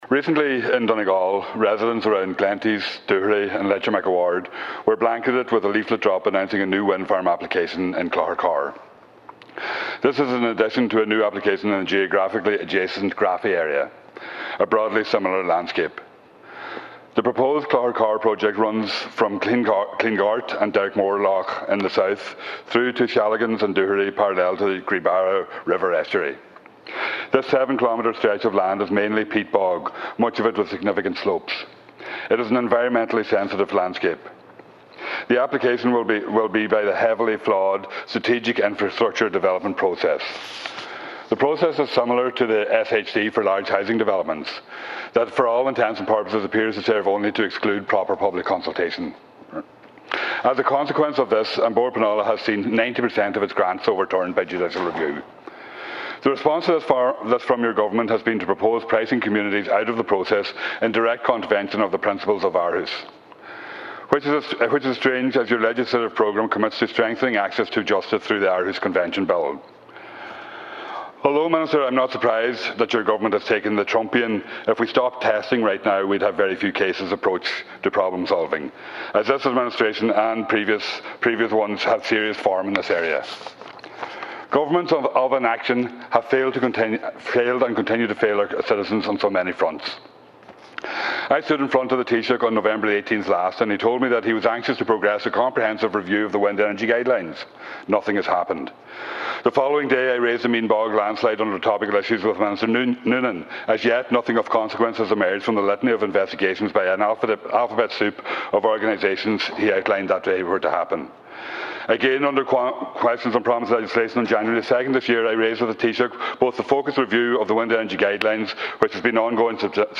In the Dail, Deputy Pringle raised the prospect of another windfarm development close to the Gweebarra, and said that and other communities should be properly involved in the planning process.
You can listen to the full exchange here –